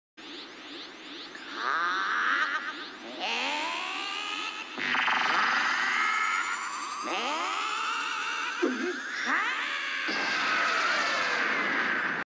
Goku Kamehameha Sound Effect Free Download